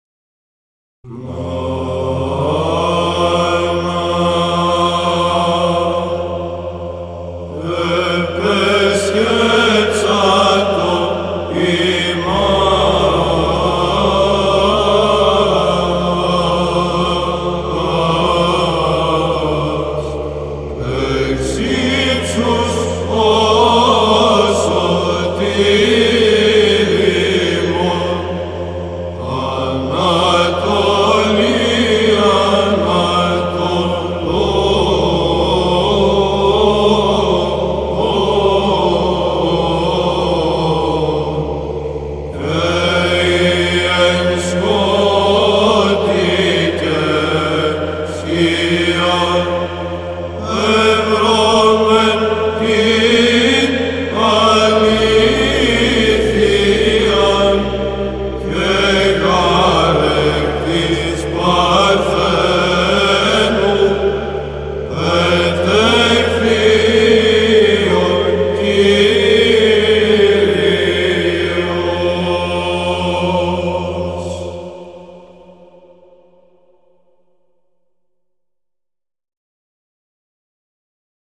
ΒΥΖΑΝΤΙΝΟΙ ΥΜΝΟΙ ΧΡΙΣΤΟΥΓΕΝΝΩΝ
εξαποστειλάριον, ήχος γ΄